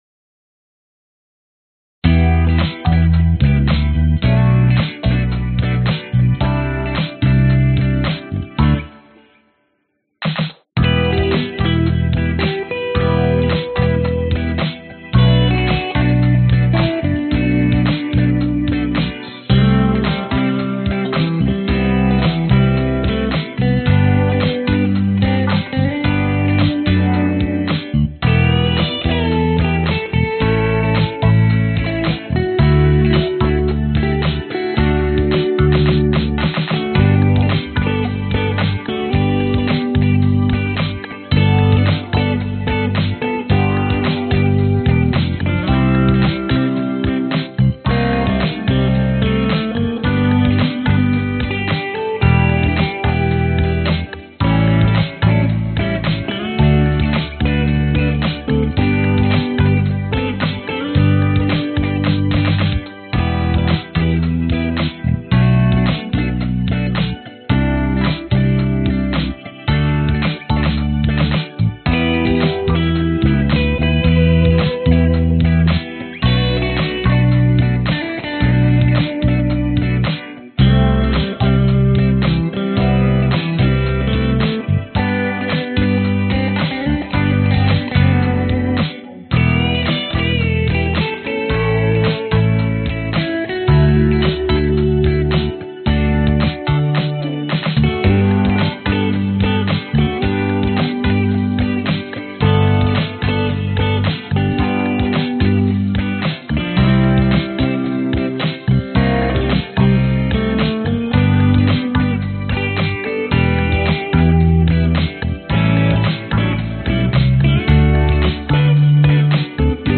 描述：E小调短曲110BPM
Tag: 吉他 贝斯 钢琴 风琴